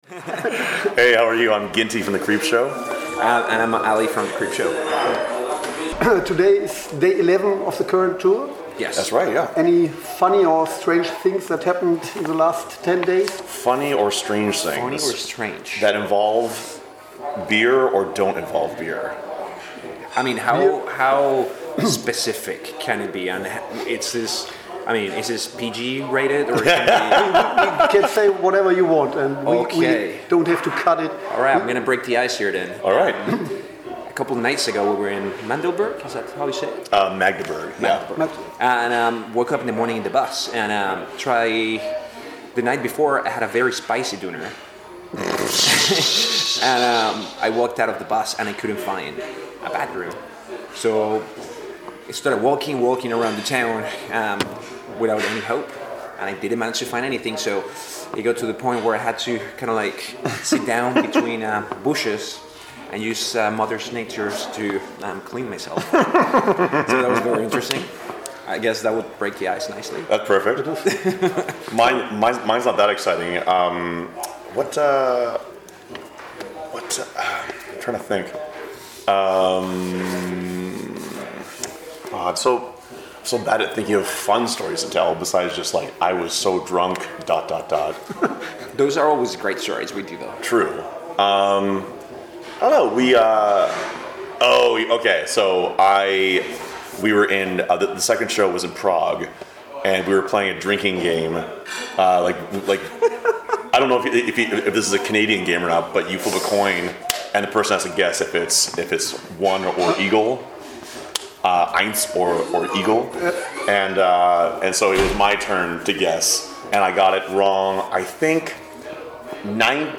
Letzte Episode Interview The Creepshow @ Rockpalast Bochum 5. Oktober 2019 Nächste Episode download Beschreibung Teilen Abonnieren The Creepshow aus Toronto waren mal wieder zu Gast in Bochum.